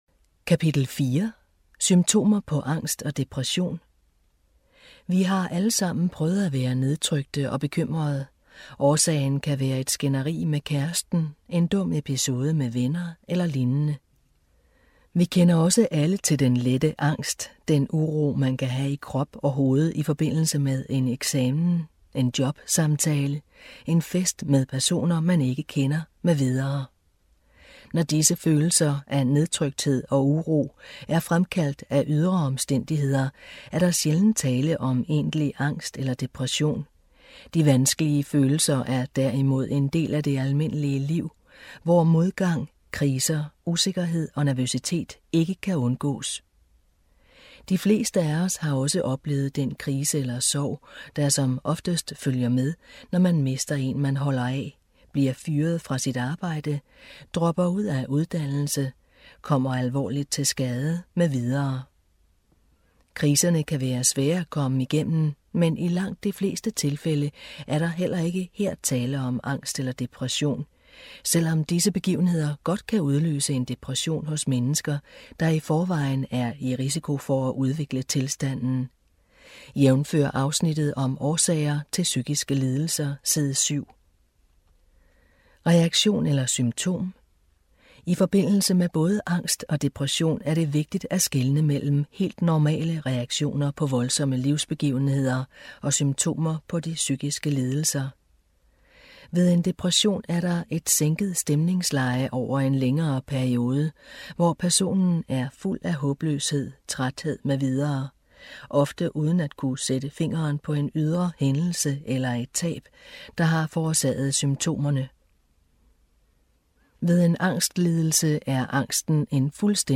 Genre: Audiobook.